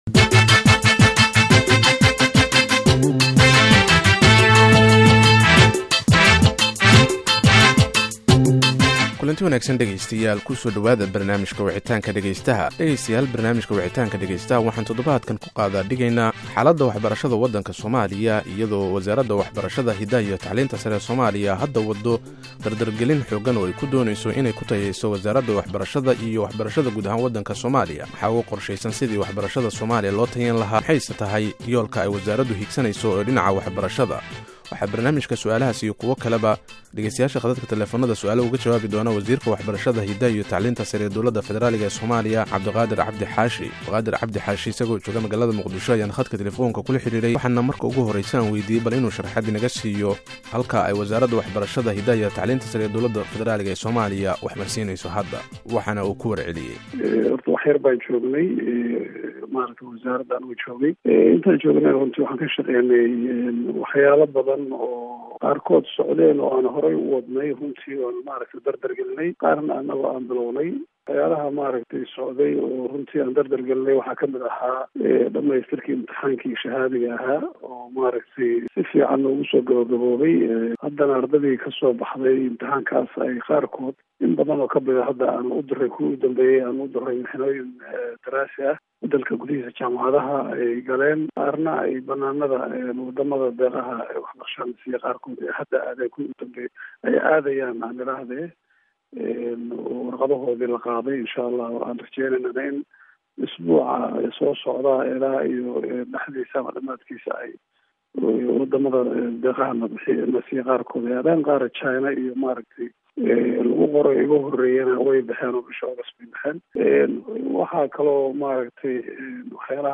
Barnaamijka Wicitaanka dhageystaha todobaadkaan, waxaa marti inoogu ah waziirka waxbarshada, hidaha iyo tacliinta sare ee Soomaaliya Cabdulqadir Cabdi Xaashi.